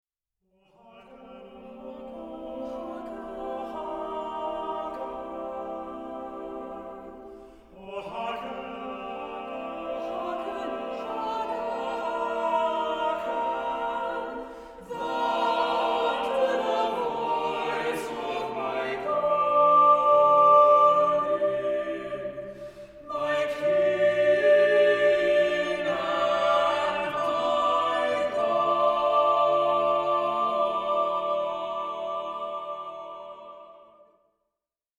choral music